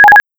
calvary-charge-loop.wav